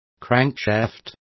Complete with pronunciation of the translation of crankshafts.